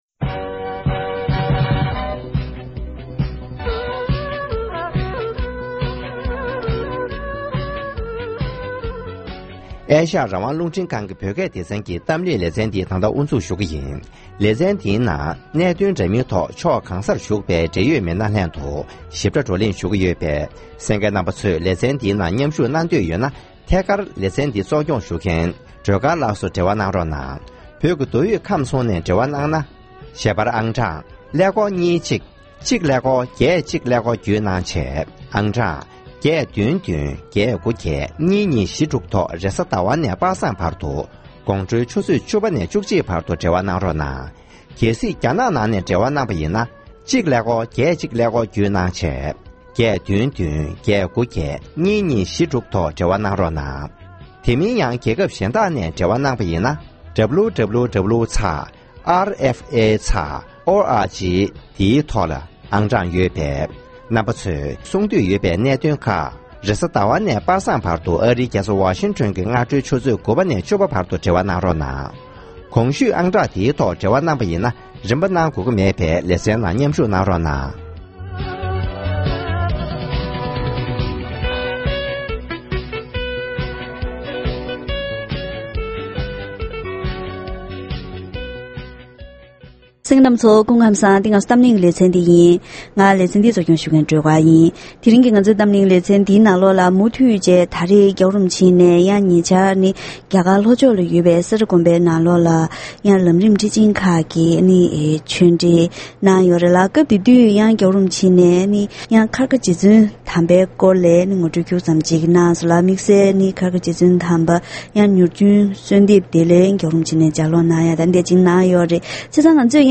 ༄༅༎དེ་རིང་གི་གཏམ་གླེང་ལེ་ཚན་ནང་ཉེ་ཆར་༸གོང་ས་མཆོག་ནས་ལམ་རིམ་ཁྲི་ཆེན་ཁག་གི་གསུང་ཆོས་གནང་སྐབས་ཁལ་ཁ་རྗེ་བཙུན་དམ་པའི་མྱུར་འབྱོན་གསོལ་འདེབས་ངོ་སྤྲོད་གནང་བ་དང་དུས་མཚུངས་རྗེ་བཙུན་དམ་པ་རིན་པོའི་ཆེ་དང་༸གོང་ས་མཆོག་ཐུགས་འབྲེལ་བྱུང་སྟངས་སོགས་ངོ་སྤྲོད་གནང་ཡོད་པས་བཀའ་སློབ་གནང་བ་ཁག་ཟུར་བཏོན་ཞུས་པ་དང་སྦྲགས་རྗེ་བཙུན་དམ་པ་རིན་པོ་ཆེའི་སྐོར་ལ་ངོ་སྤྲོད་ཞུས་པ་ཞིག་ཡིན།།